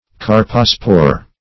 carpospore - definition of carpospore - synonyms, pronunciation, spelling from Free Dictionary
Search Result for " carpospore" : Wordnet 3.0 NOUN (1) 1. a nonmotile spore of red algae ; The Collaborative International Dictionary of English v.0.48: Carpospore \Car"po*spore\, n. [Gr. karpo`s + -spore.]